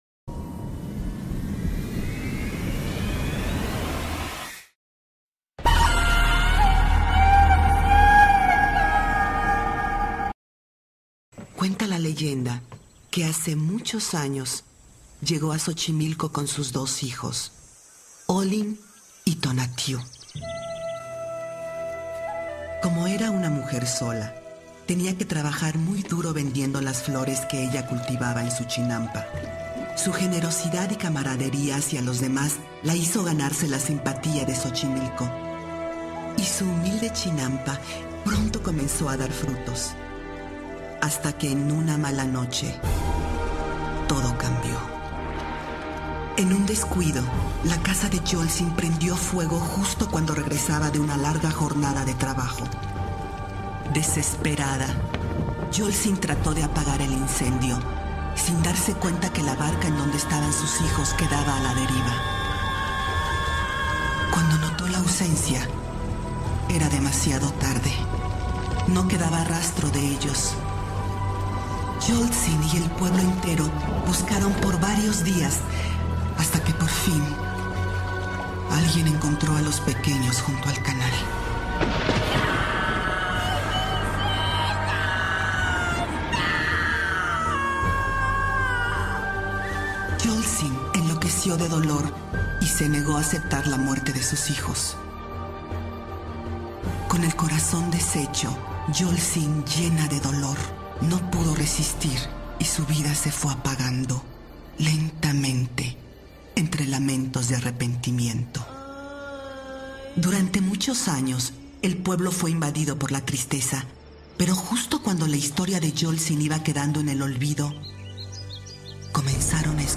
Audio+Cuento+De+Terror+Para+Niños+La+Llorona+ +Semana+Del+Terror+En+Di+Edge+Vlogs (audio/mpeg)